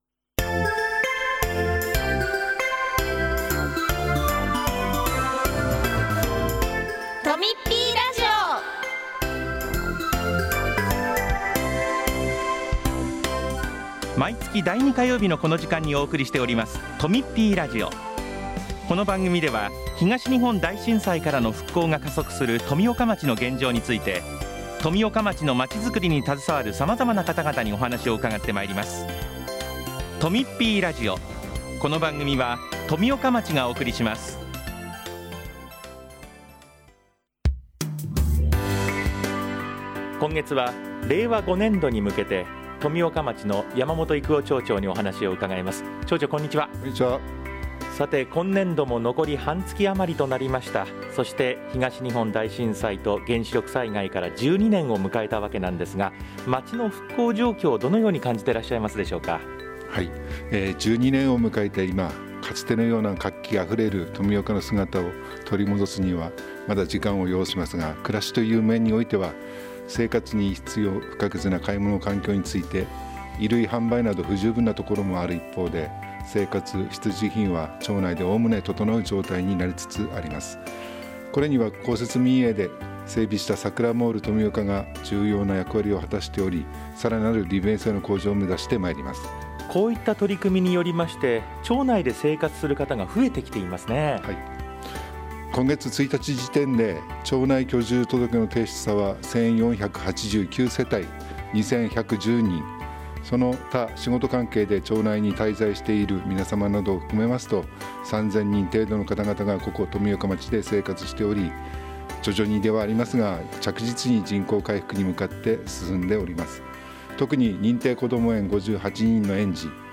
今回は、令和5年度の町の取り組みなどについて、山本育男町長が紹介します。その他、町からのお知らせもあります。